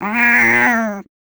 sounds / monsters / cat / 6.ogg